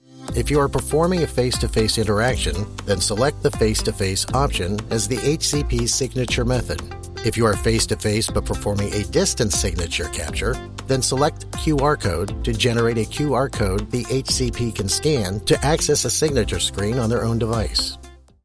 Male
Adult (30-50), Older Sound (50+)
E-Learning
Elearning Video Voice Over